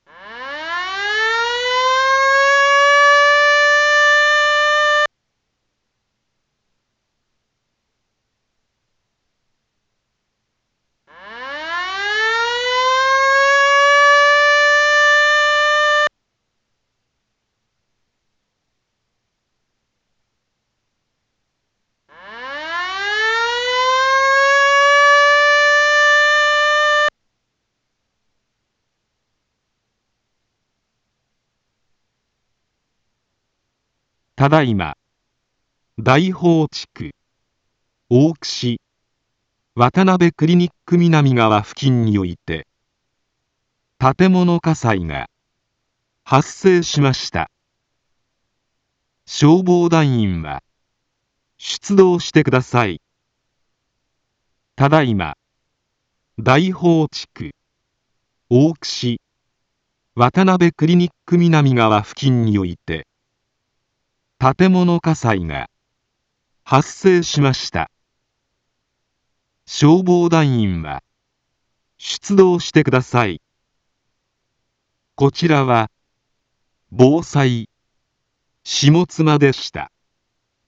一般放送情報
Back Home 一般放送情報 音声放送 再生 一般放送情報 登録日時：2023-12-23 11:09:24 タイトル：火災報 インフォメーション：ただいま、大宝地区、大串、渡辺クリニック南側 付近において、 建物火災が、発生しました。